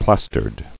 [plas·tered]